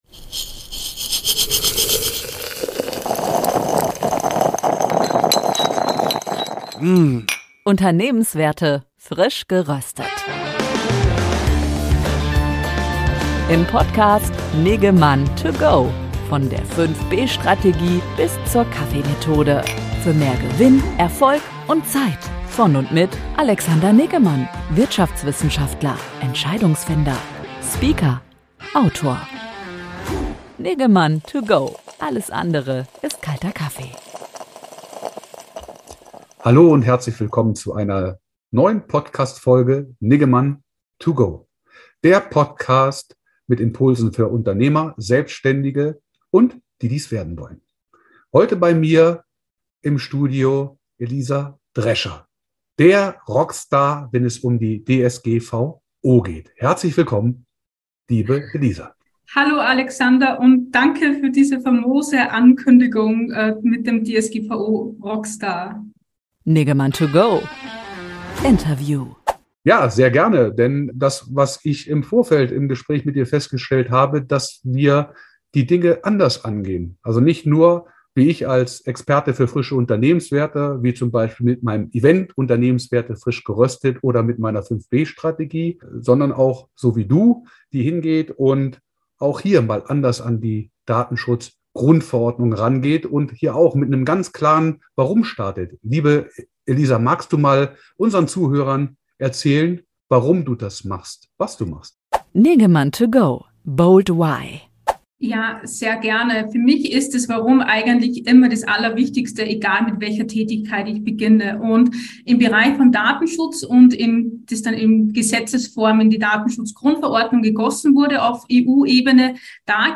Interviewfolge